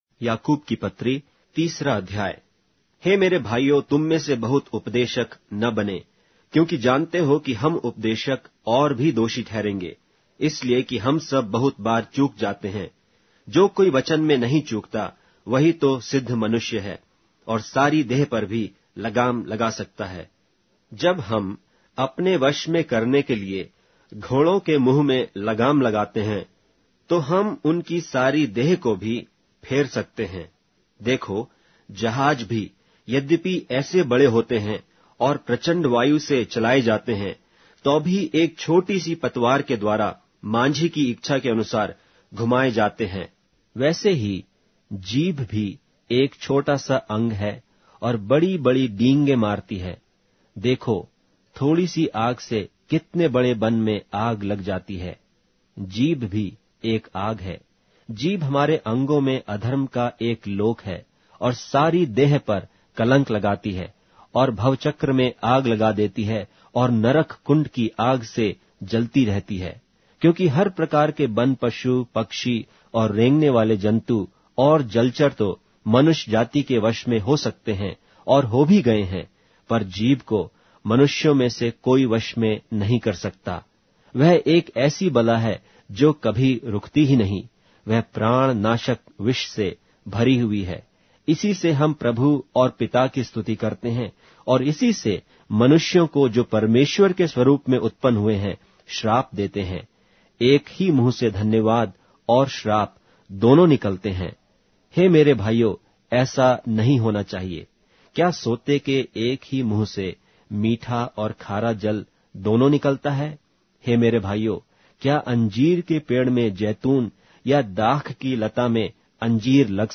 Hindi Audio Bible - James 2 in Lxxen bible version